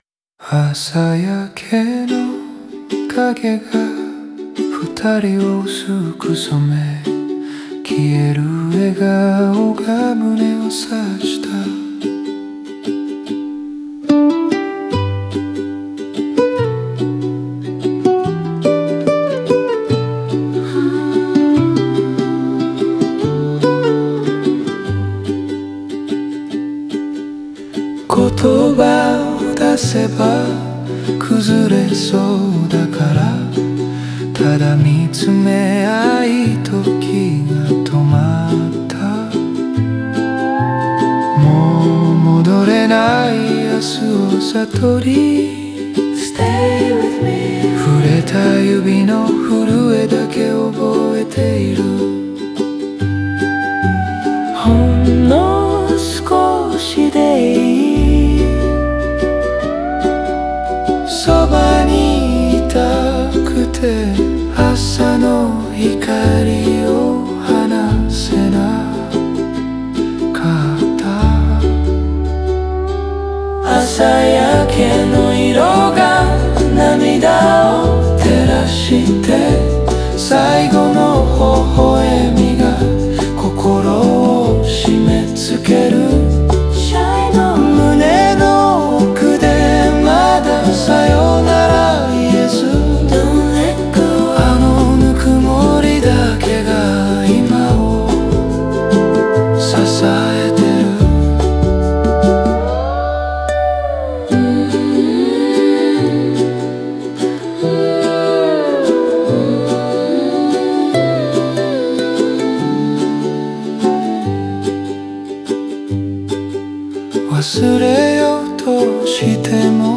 オリジナル曲♪
作品全体は、抑えた語り口によって、聴く者の心にゆっくり浸透するよう構成されている。